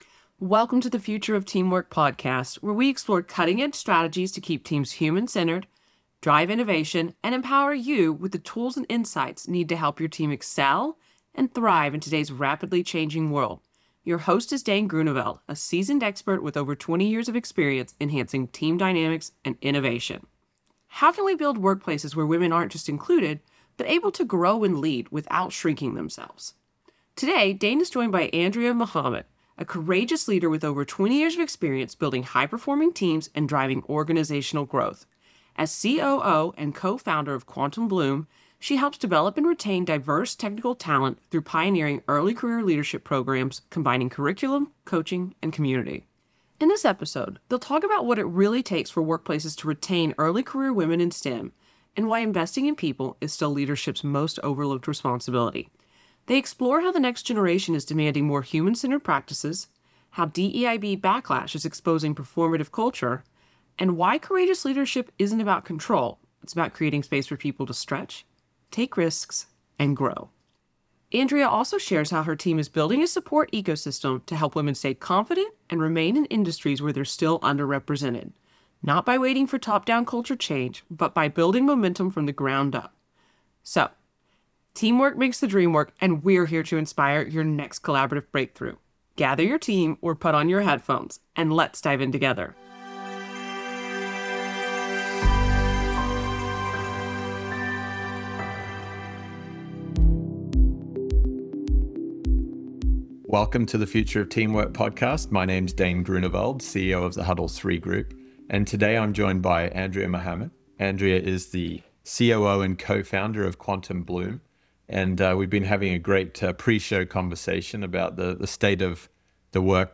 Together, they uncover the disconnect between how organizations say they value people and how they actually treat them – and why today’s emerging workforce is no longer willing to tolerate the mismatch. From generational shifts in mindset to the economics of inclusion, this conversation is a must-listen for anyone invested in the future of work.